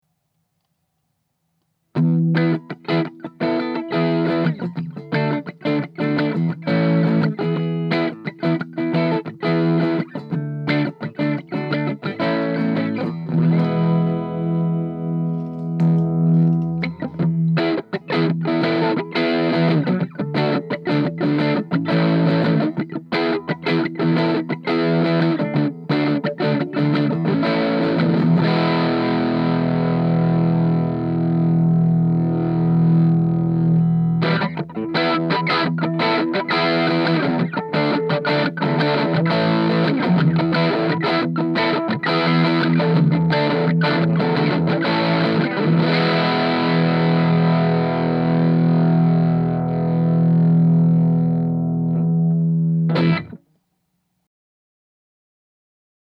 In the next clip, I cover more dynamics; basically following the same pattern as the first: Amp only, guitar volumes at 5, then pedal enaged, then bridge pickup cranked:
The thing that’s very noticeable in the clips above is that the pedal loves a lot of input gain, and like a tube amp, with more input gain, reveals more sonic content in the form of harmonics and overtones.
mojo_input_gain.mp3